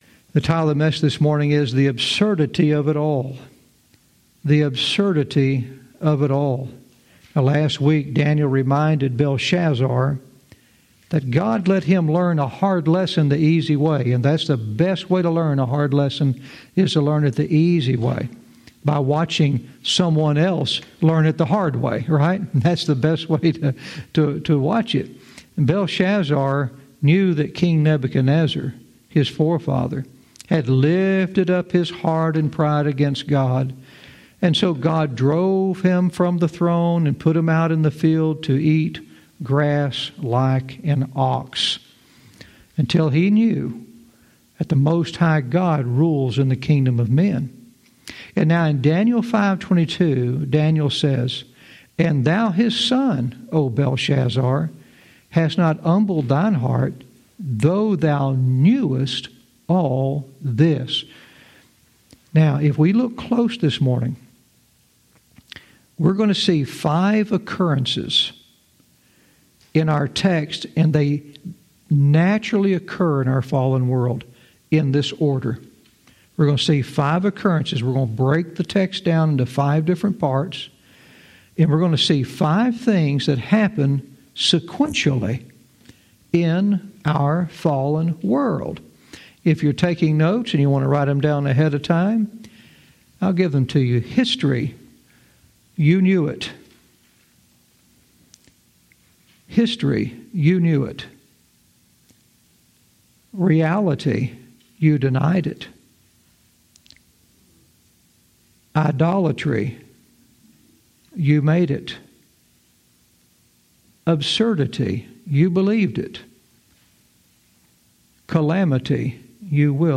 Verse by verse teaching - Daniel 5:23-25 "The Absurdity of it All"